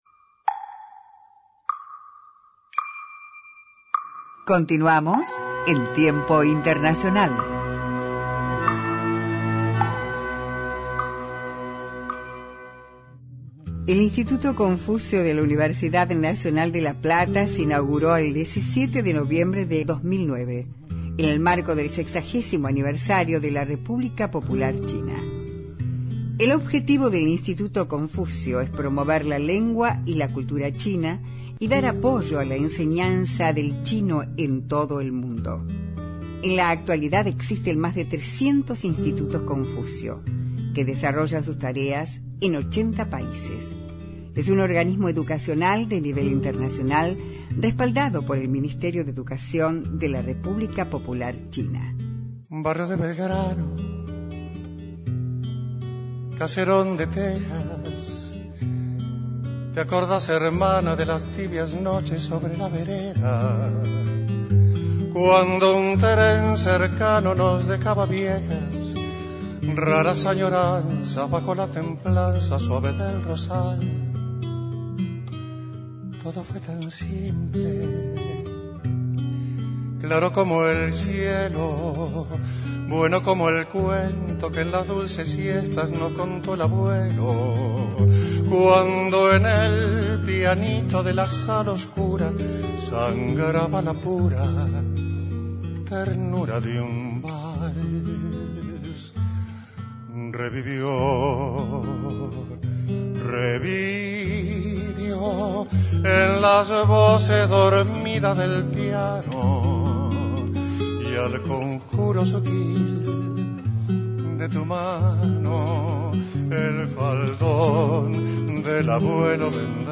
Tiempo Internacional entrevistó